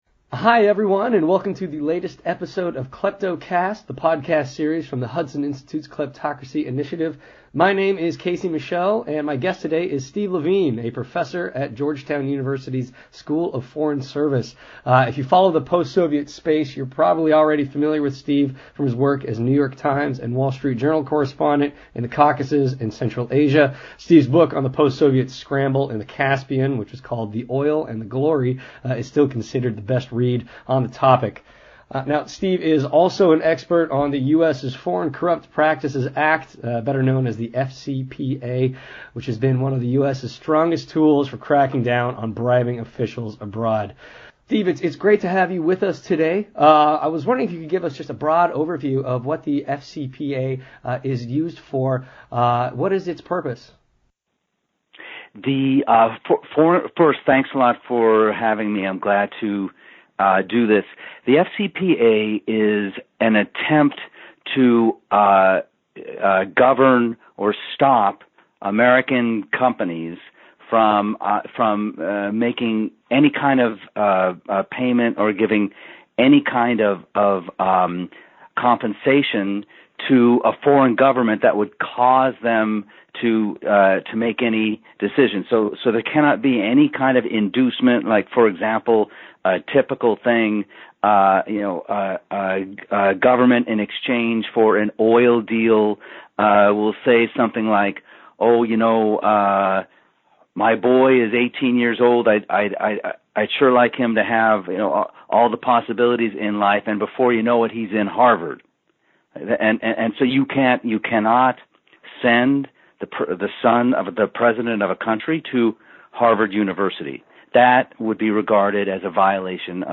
FCPA-Podcast-FINAL.mp3